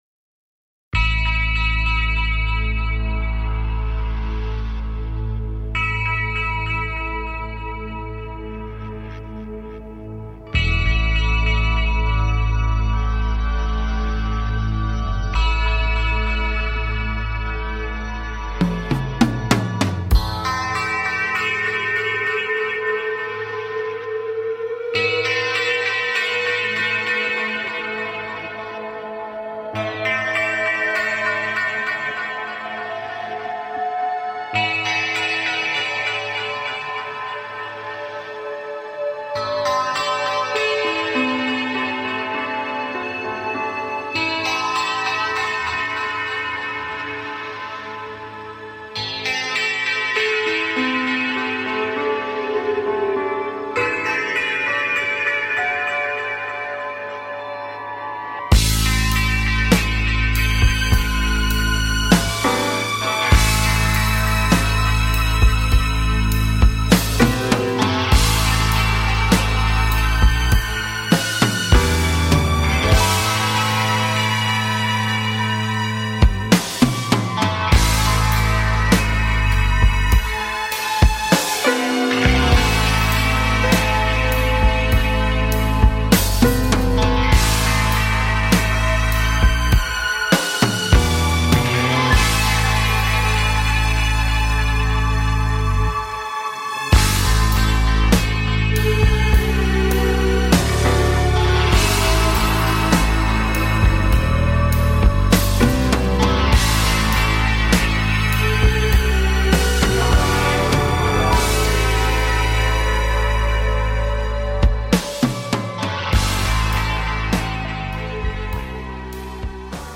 CLICK HERE SUBSCRIBE TO TALK SHOW